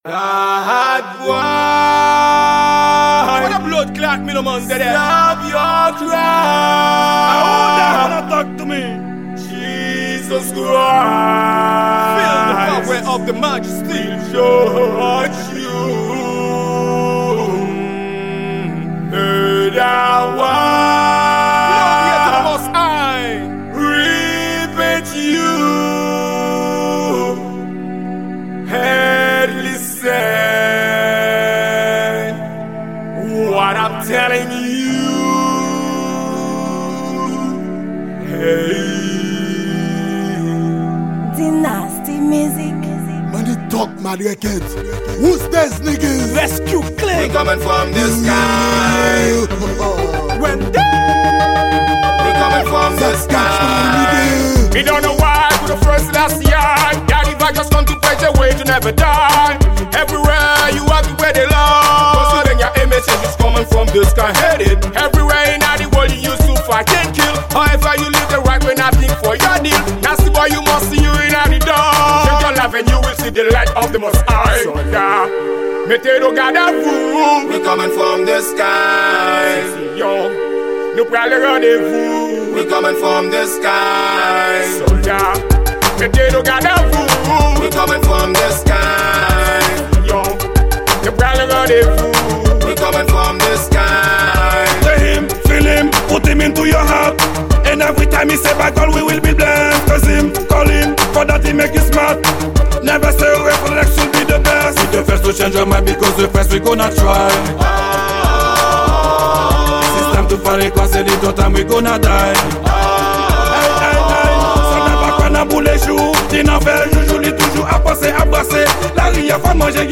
Genre: Dancehal.